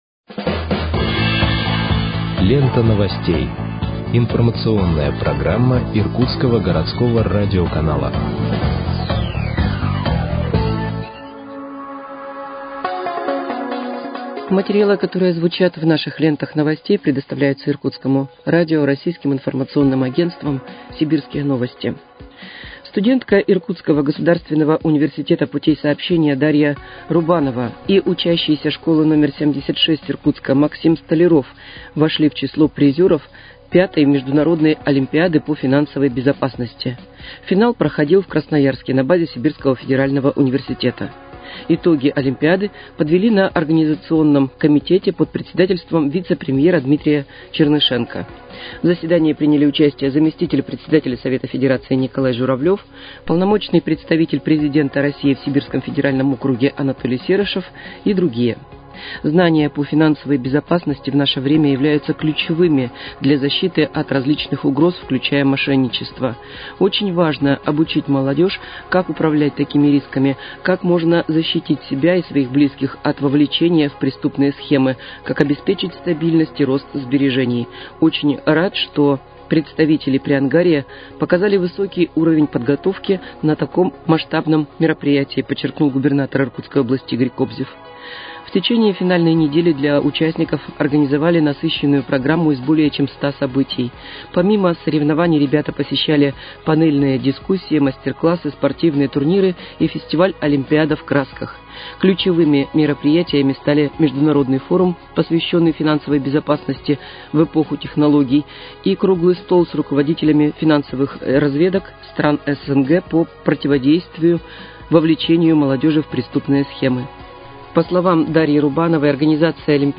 Выпуск новостей в подкастах газеты «Иркутск» от 13.10.2025 № 1